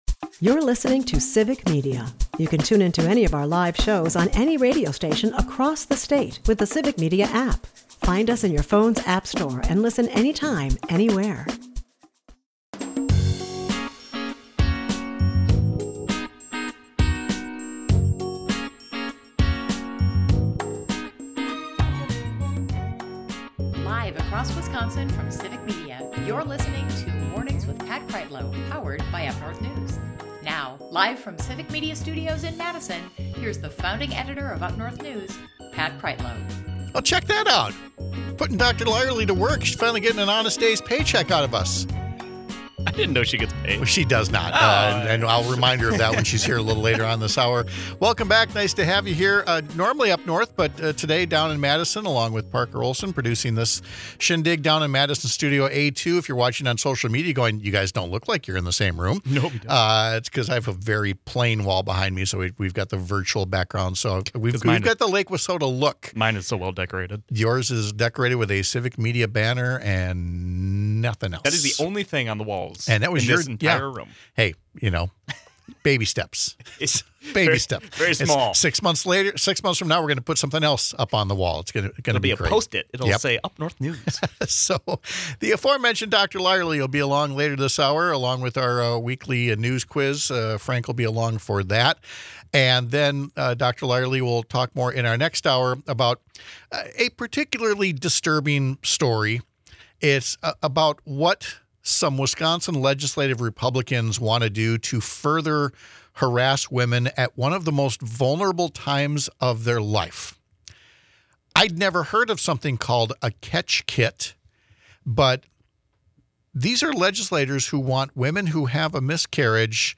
At the end of the hour we play a week in review game.